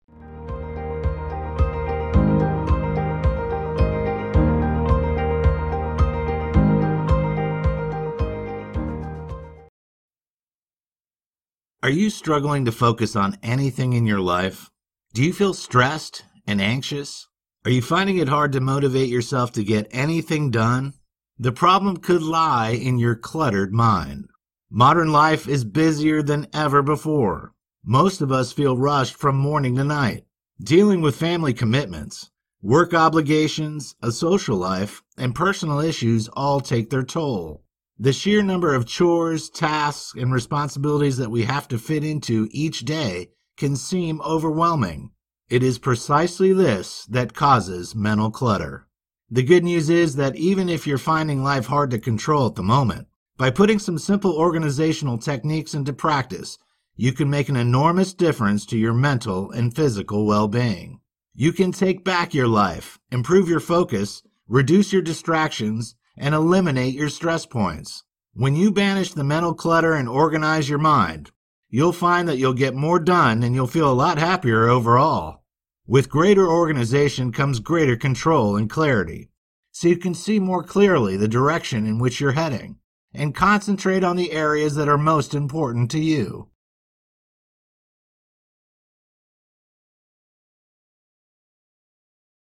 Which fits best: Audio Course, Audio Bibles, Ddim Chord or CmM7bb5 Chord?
Audio Course